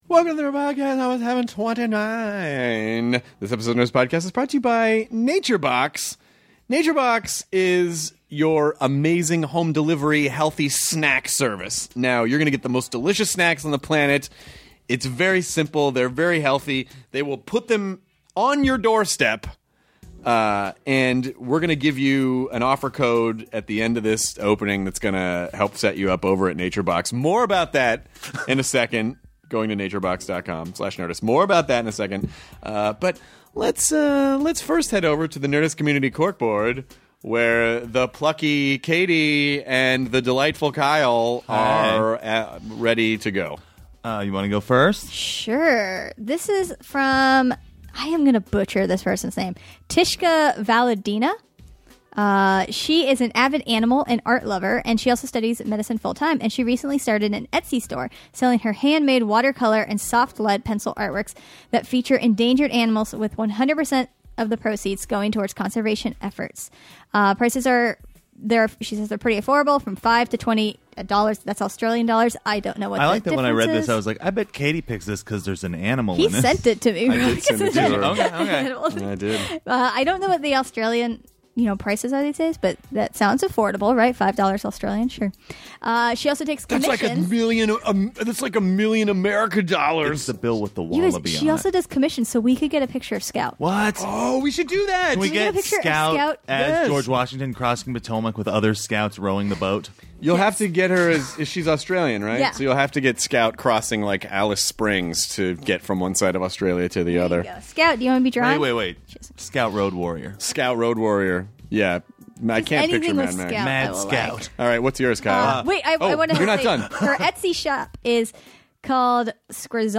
Rob Huebel (comedian, Children's Hospital) and Paul Scheer (comedian, The League) chat with Chris about their excitement for the new Star Wars toys, the original UCB theater that took over a strip club and their favorite billboards for new TV shows. They also talk about their love of ghost hunter shows and their comedy special on a bus, Crash Test!